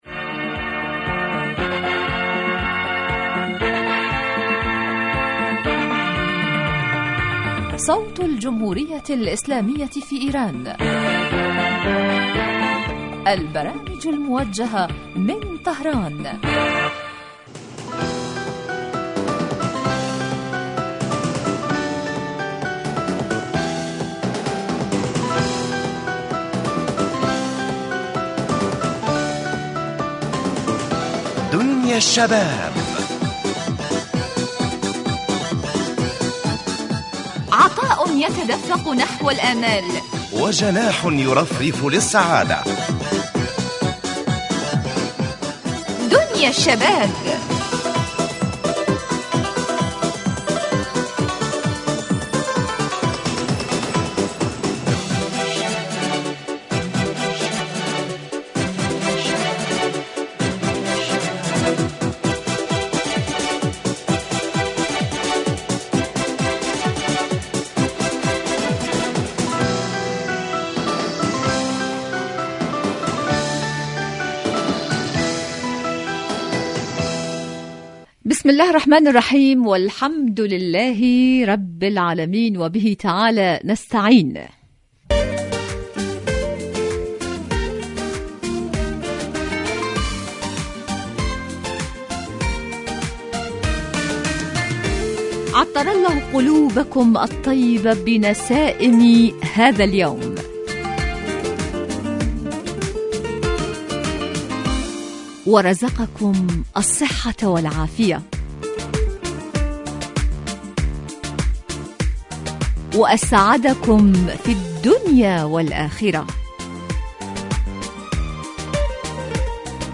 برنامج اجتماعي غني بما يستهوي الشباب من البلدان العربية من مواضيع مجدية و منوعة و خاصة ما يتعلق بقضاياهم الاجتماعية وهواجسهم بالتحليل والدراسة مباشرة علي الهواء.